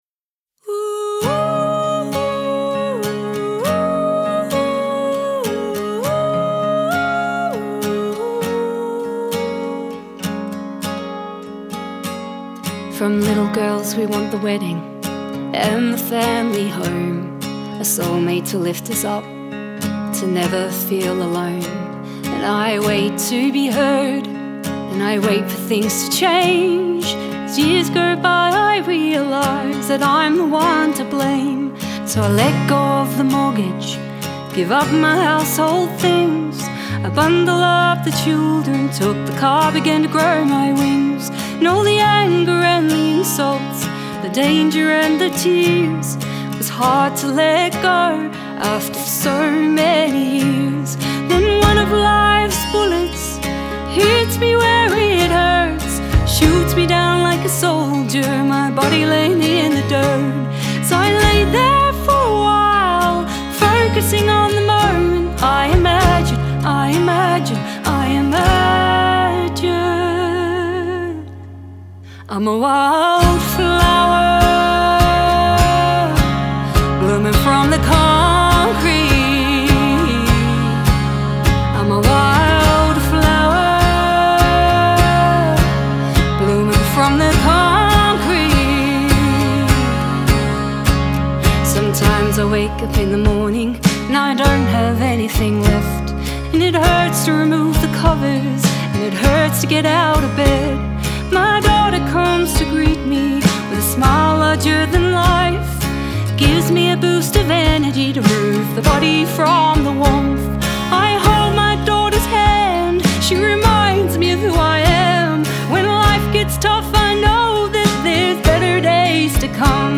heartfelt, uplifting rock ballad